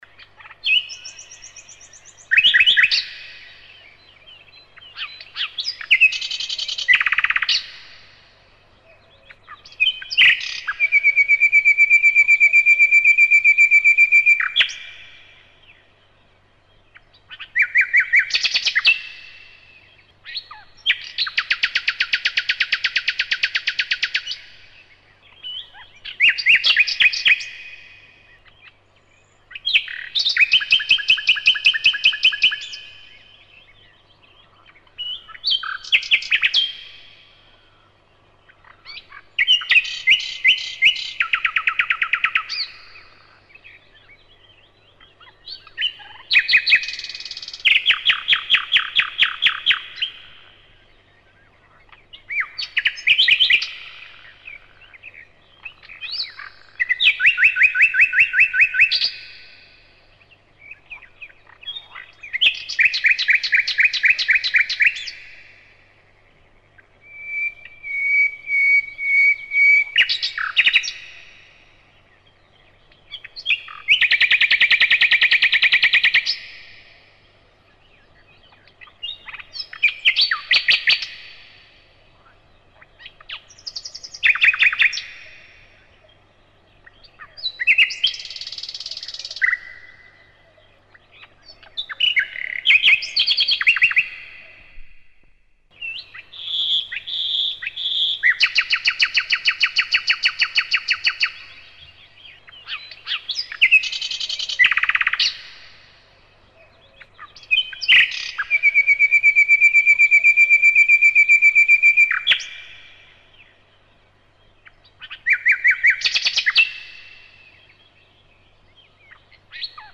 Звуки пения птиц